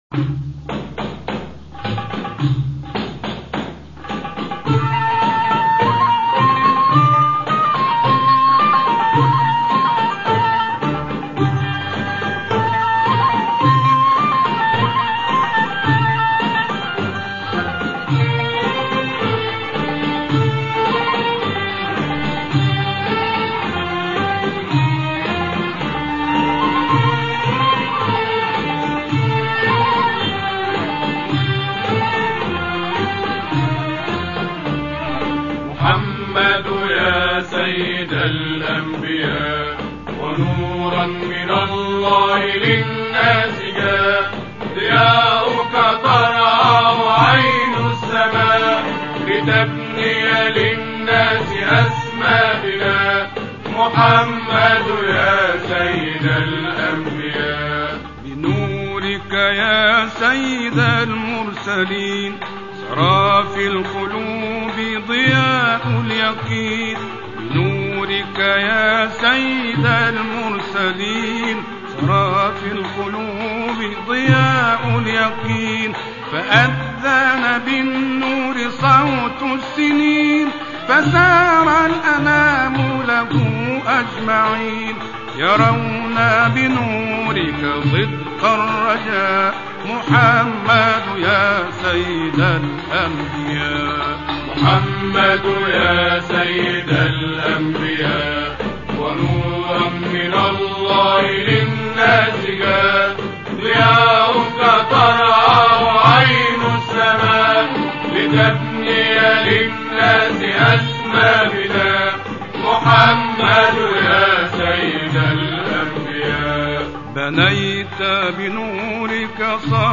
گروه تواشیح بقیه الله تهران